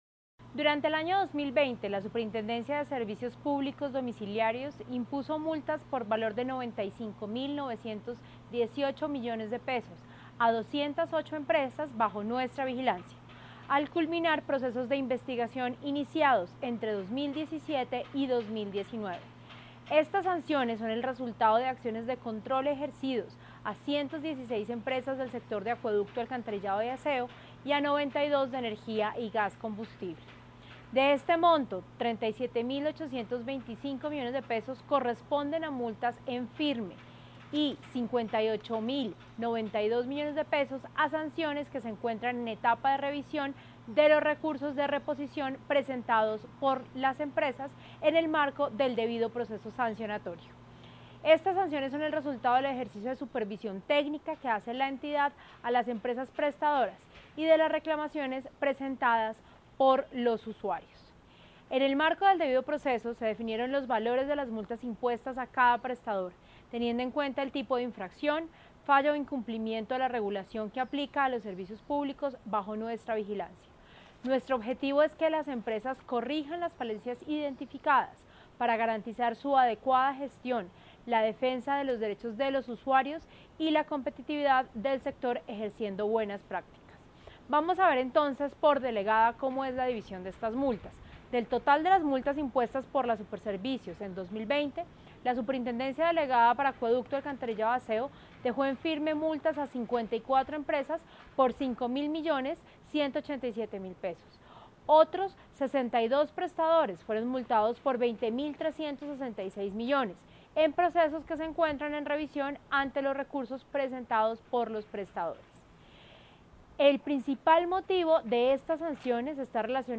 Declaraciones de la superintendente Natasha Avendaño García